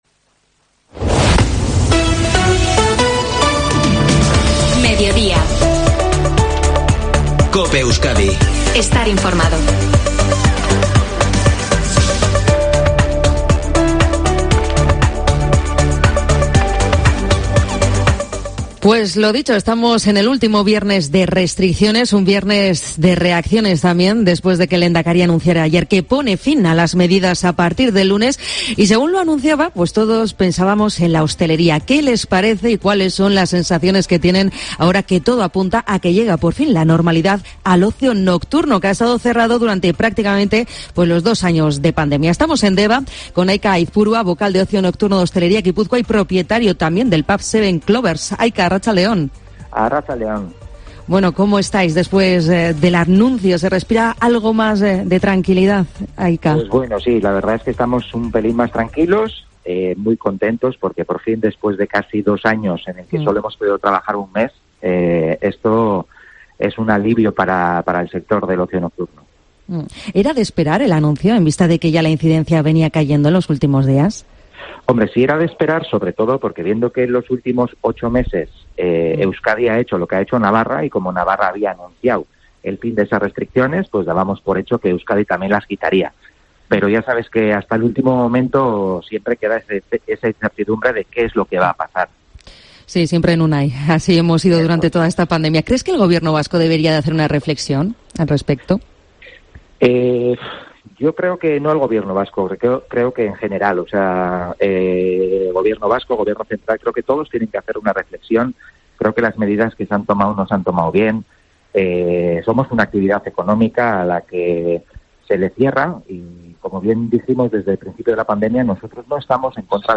En una entrevista